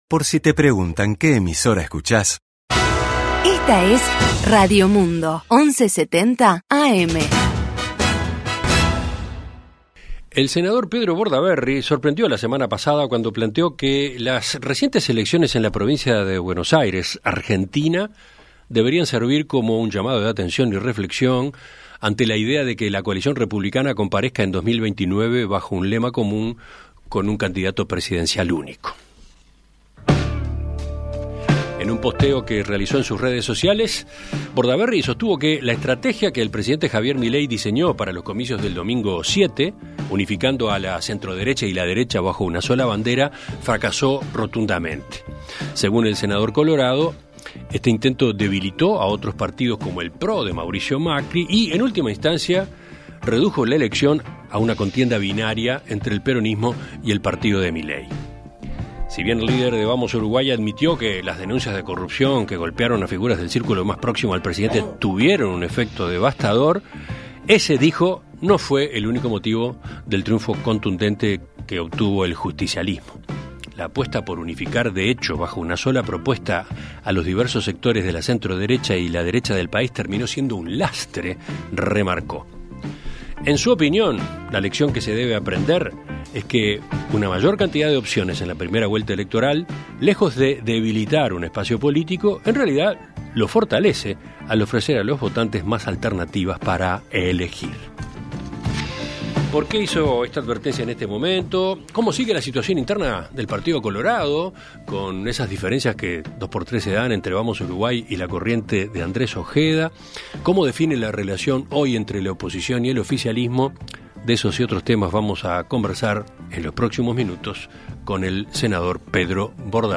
En Perspectiva Zona 1 – Entrevista Central: Pedro Bordaberry - Océano
Conversamos con el Senador Pedro Bordaberry.